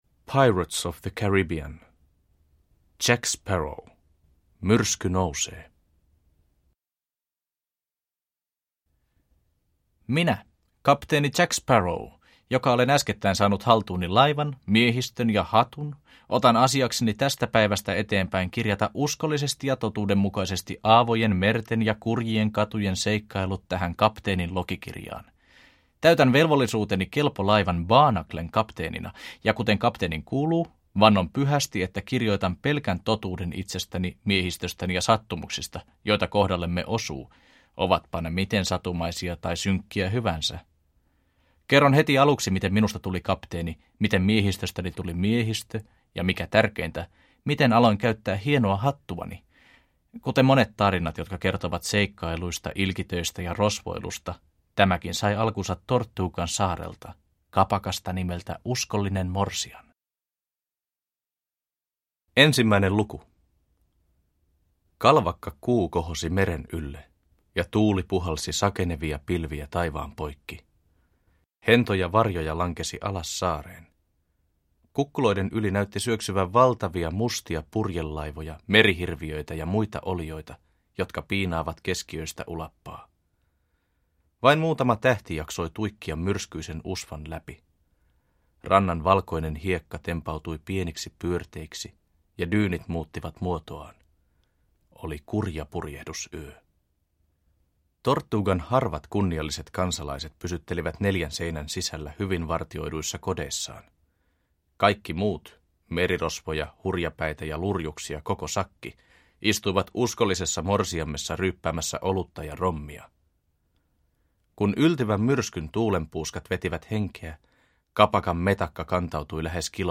Jack Sparrow 1. Myrsky nousee – Ljudbok – Laddas ner